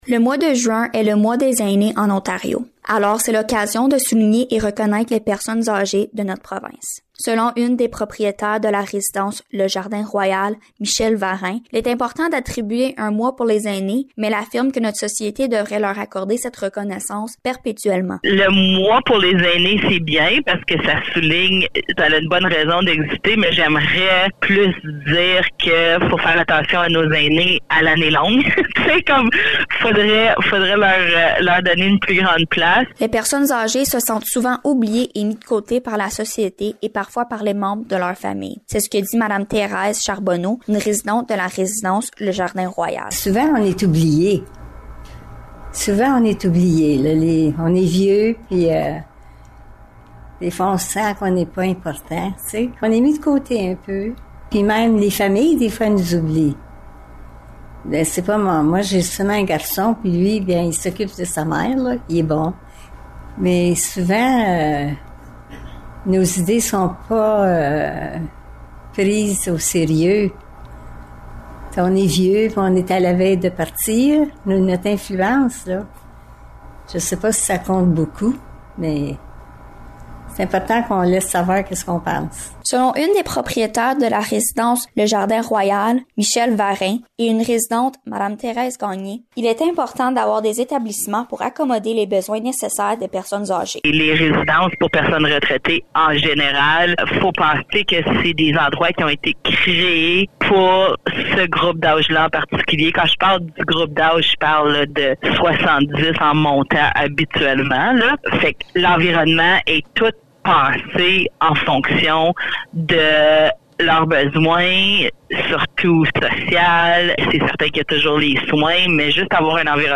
Reportage-mois-des-aines.mp3